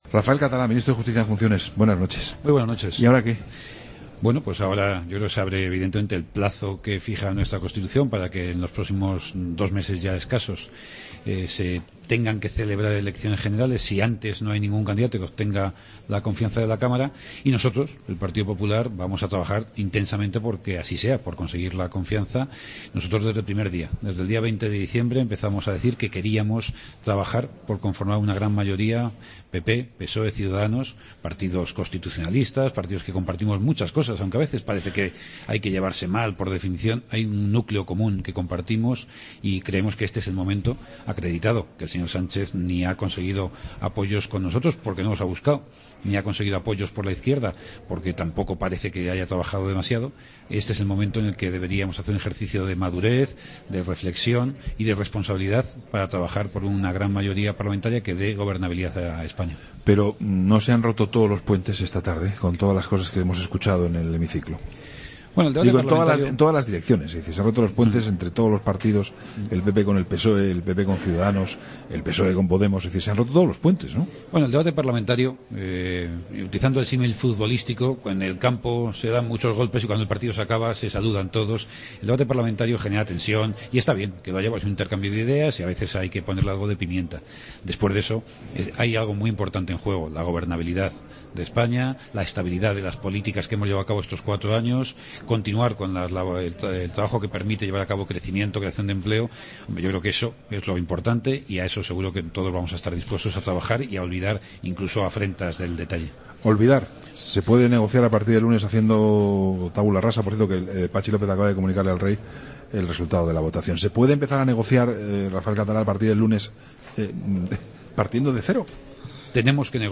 AUDIO: Escucha la entrevista a Rafael Catalá, ministro de Justicia en funciones, en La Linterna.